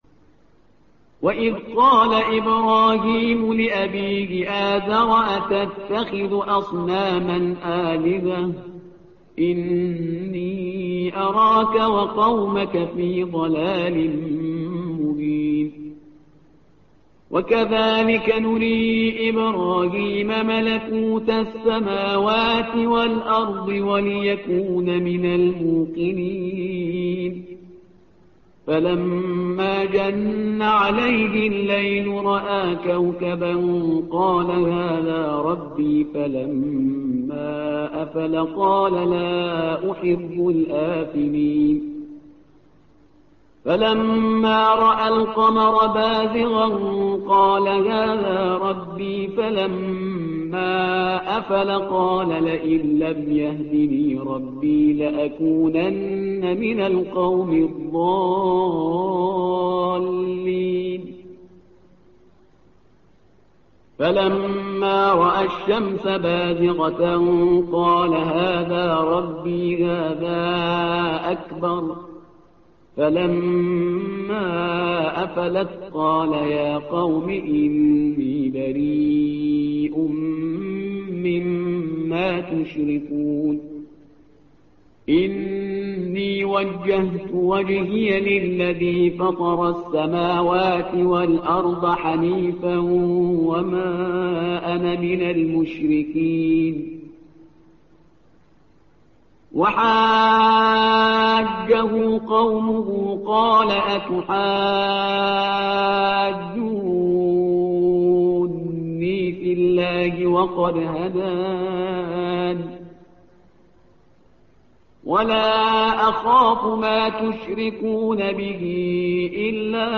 تحميل : الصفحة رقم 137 / القارئ شهريار برهيزكار / القرآن الكريم / موقع يا حسين